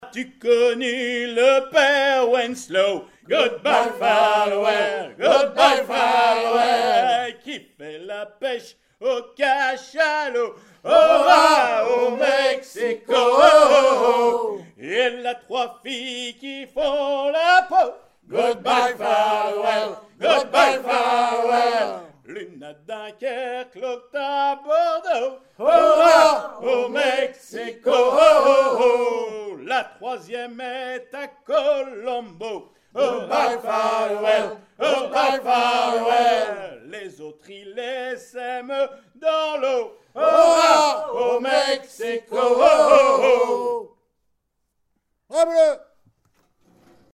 gestuel : à virer au cabestan ; gestuel : à hisser à grands coups ;
Pièce musicale inédite